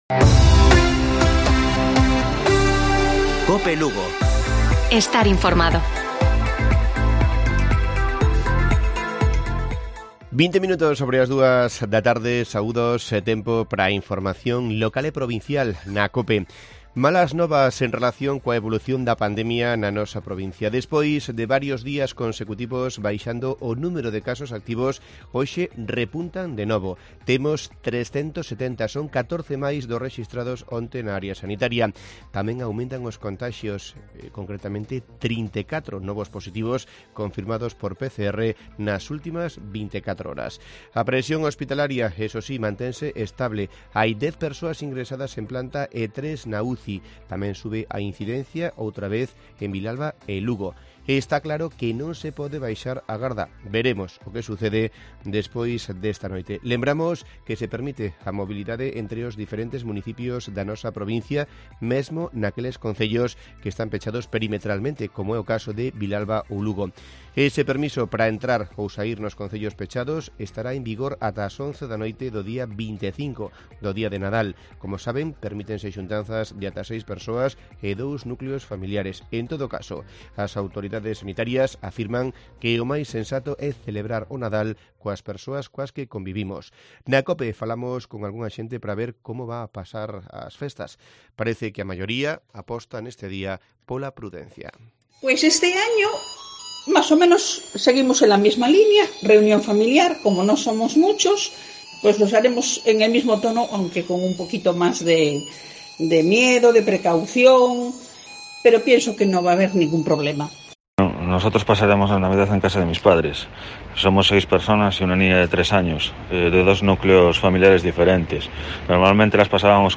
Informativo Mediodía de Cope Lugo. 24 de diciembre. 14:20 horas